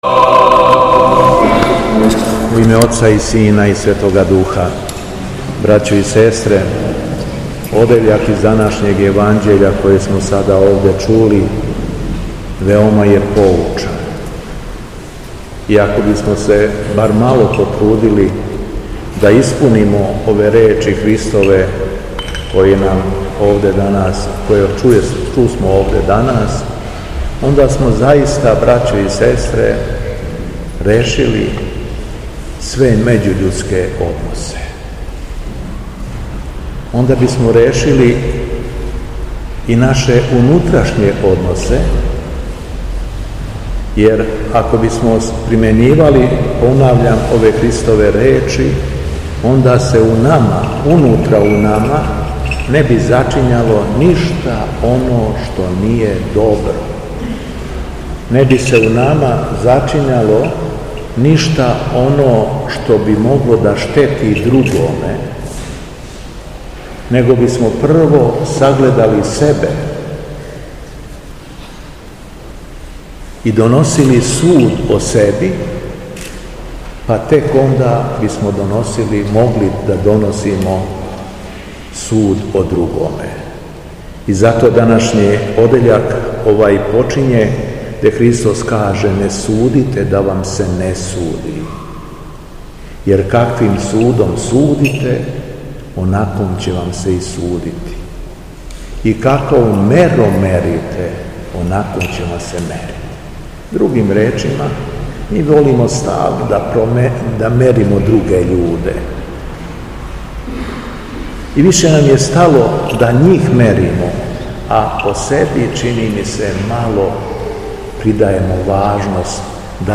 Беседа Његовог Преосвештенства Епископа шумадијског г. Јована
После прочитаног јеванђелског зачала, Владика Јован у својој беседи обраћајући се верном народу рекао је: